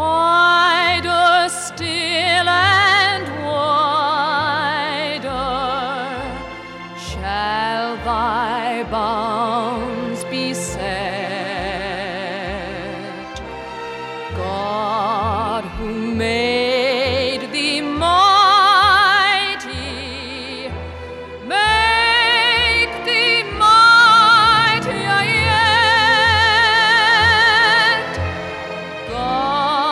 • Pop
British patriotic song